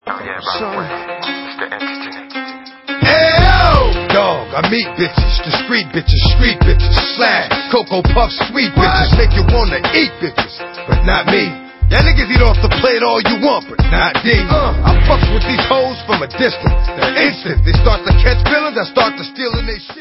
sledovat novinky v oddělení Dance/Hip Hop